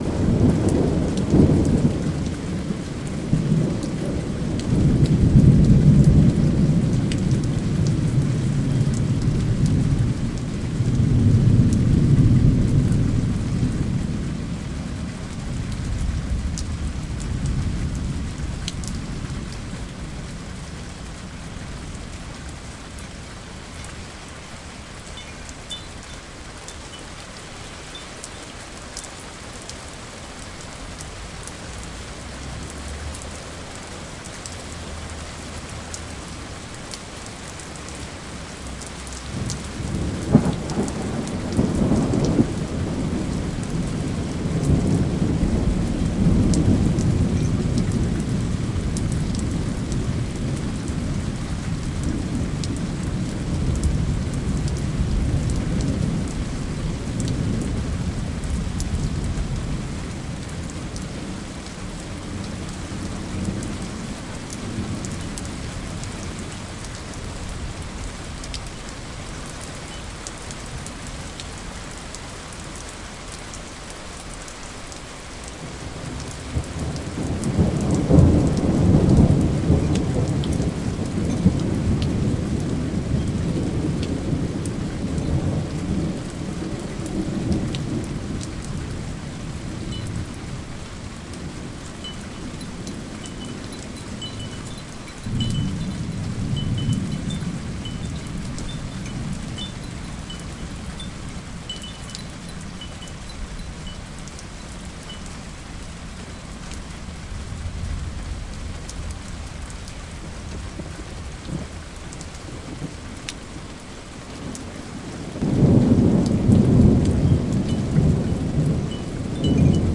音频工作 " Lluvia
描述：Sonido de lluvia con“truenos”
标签： 雷雨 风暴 天气
声道立体声